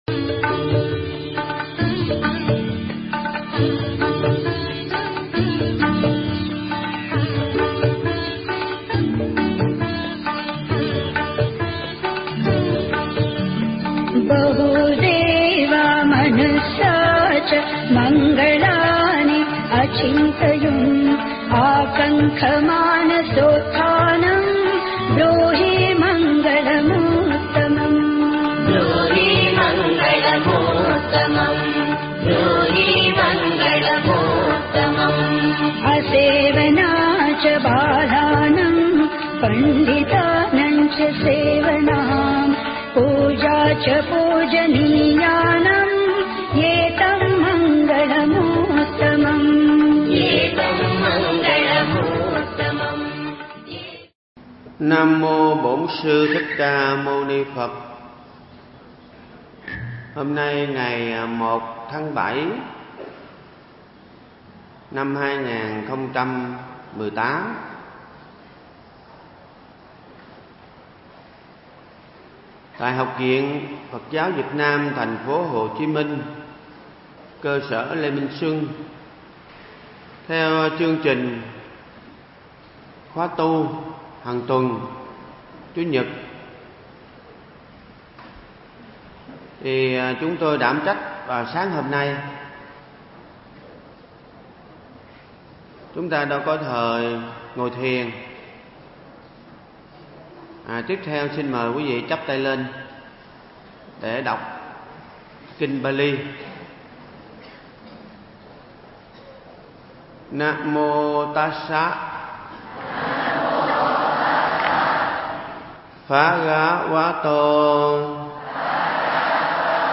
Mp3 Thuyết Pháp Chấp nhận thương đau đừng hỏi tại sao
thuyết giảng tại Học Viện Phật Giáo Việt Nam cơ sở Lê Minh Xuân